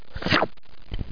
shlurp.mp3